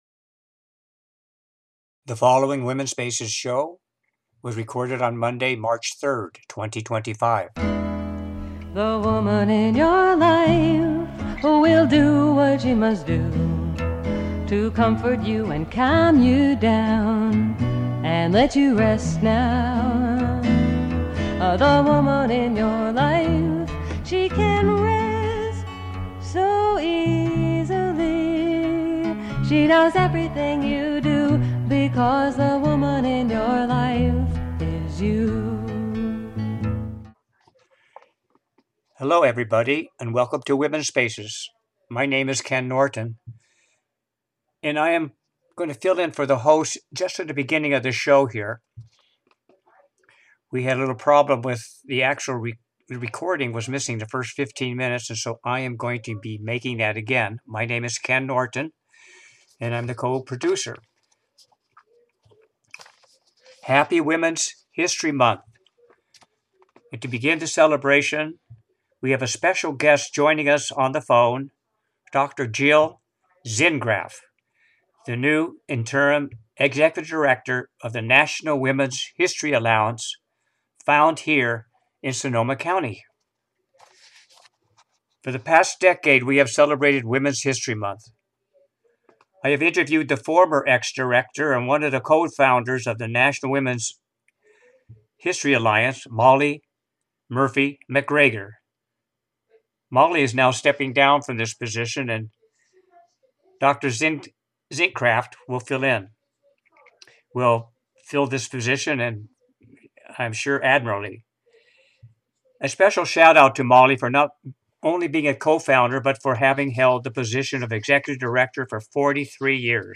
Happy Women’s History Month and to begin the celebration I have a special guest joining me on the phone